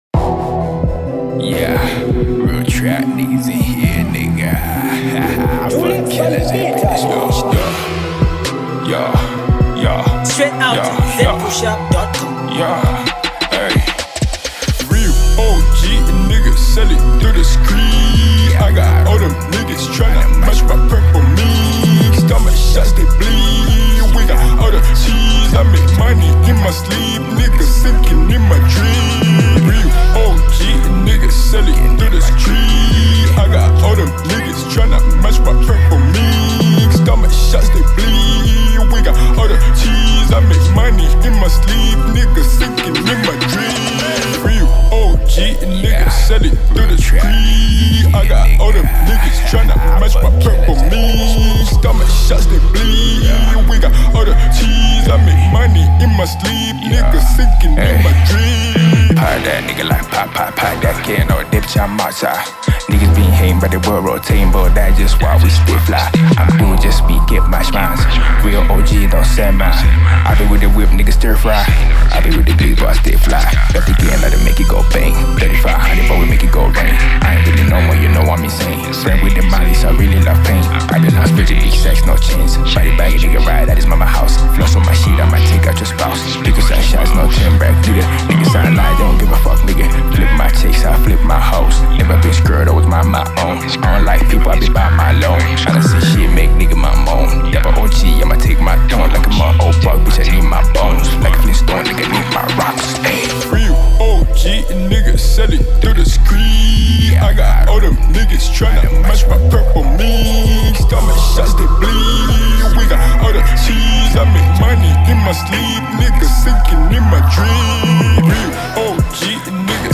New hiphop